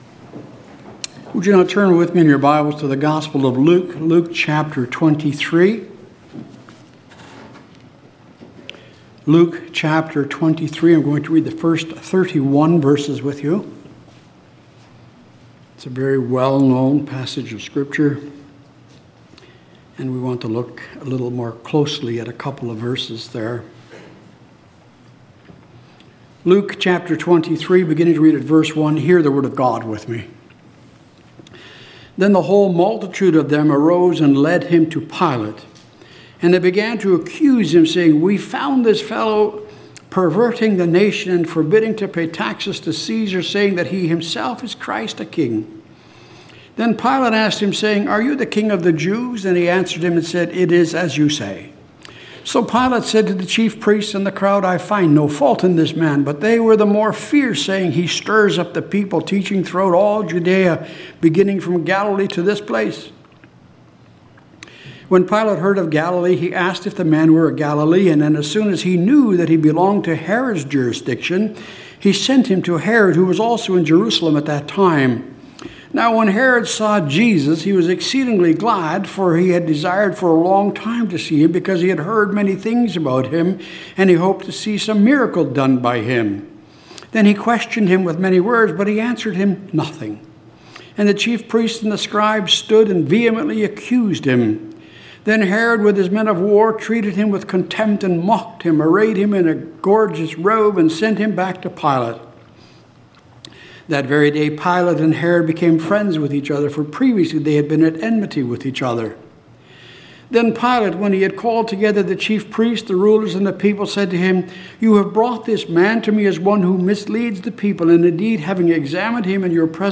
Passage: Luke 23:1-31 Service Type: Sunday Morning Jesus’ Last Word of Warning « The Sufficiency of God’s Grace Righteousness